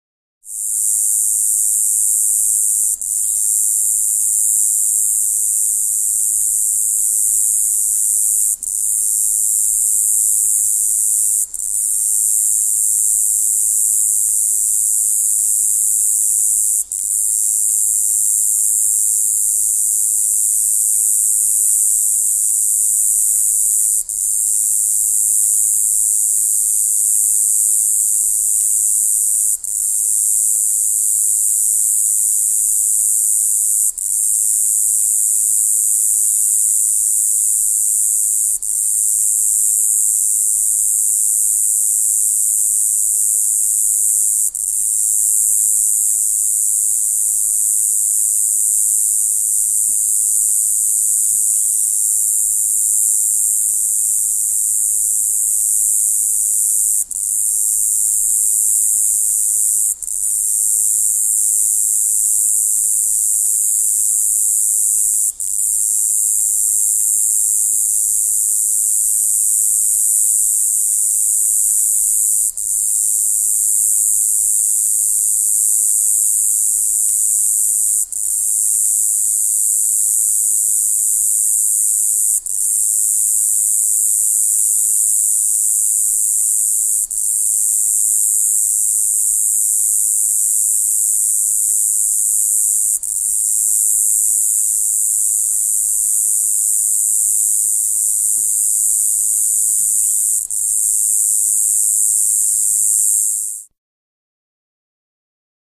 Continuous Single Cicada Ratchet With Faint Bird In Background.